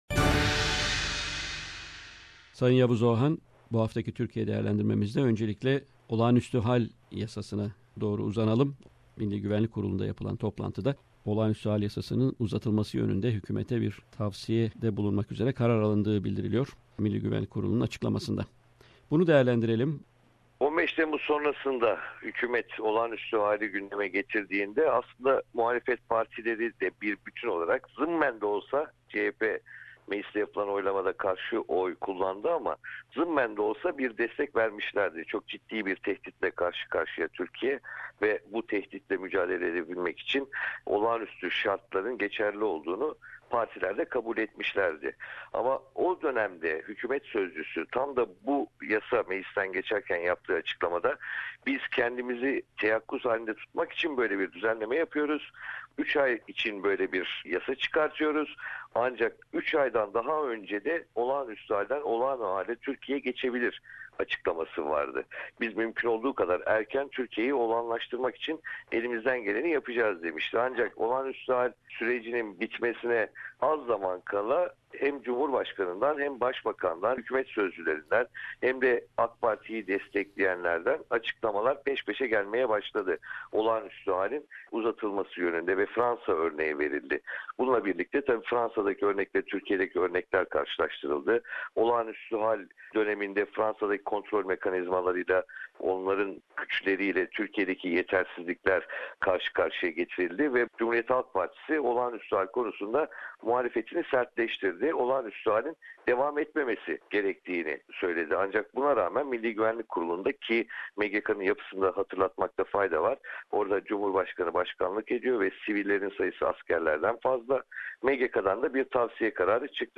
Weekly Stringer Report from Istanbul